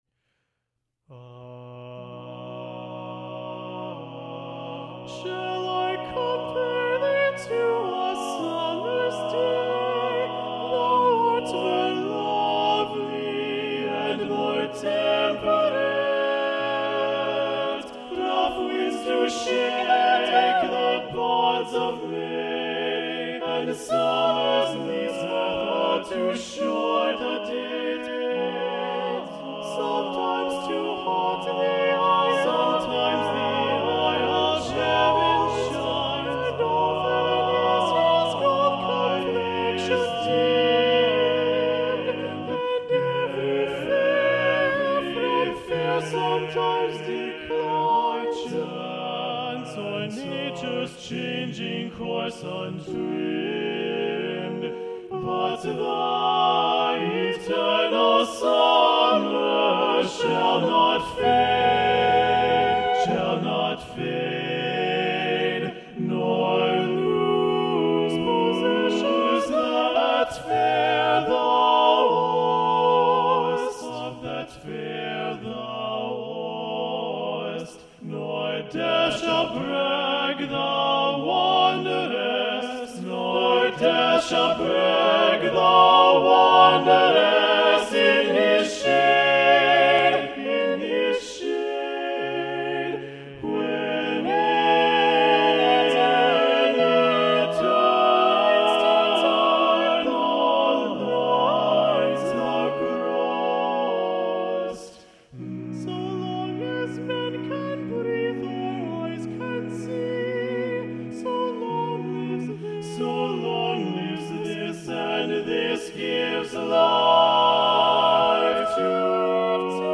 Choral ~ General ~ A Cappella
A melodic and very expressive setting